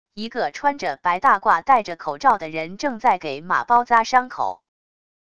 一个穿着白大褂带着口罩的人正在给马包扎伤口wav音频